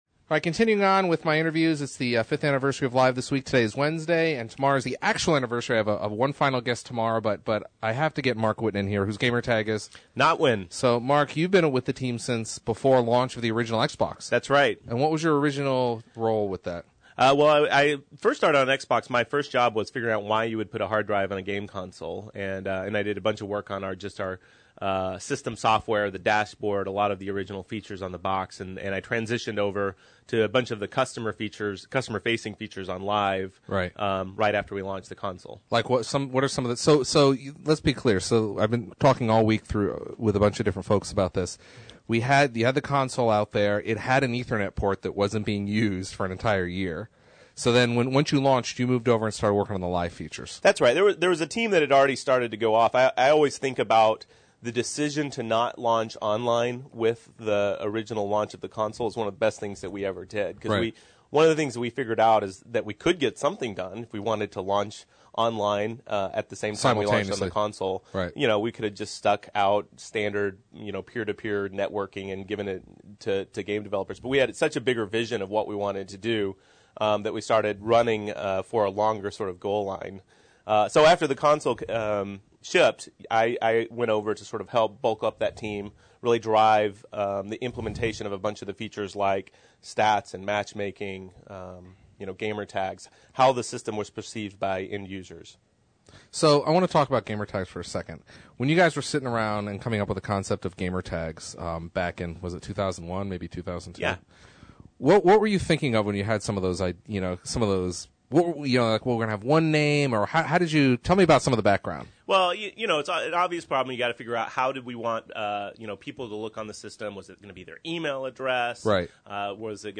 Interview: